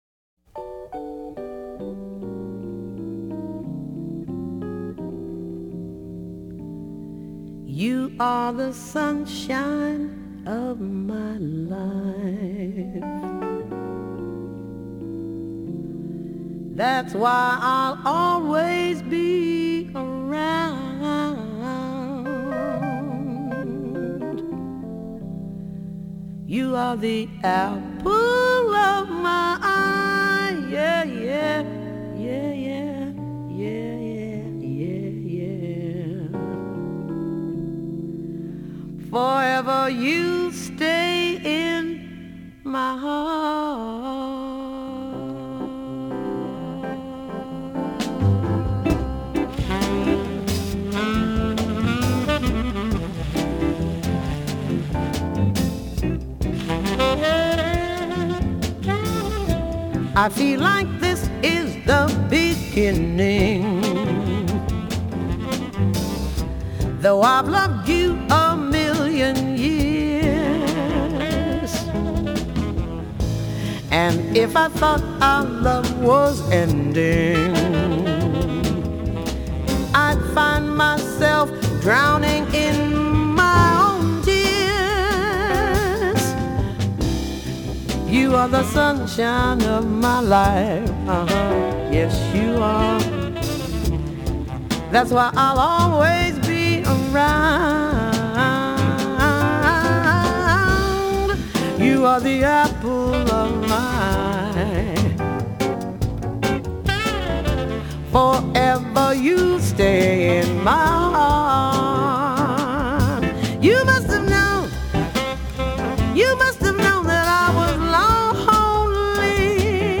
『拖拍』、『玩世不恭』與眾不同的演唱方式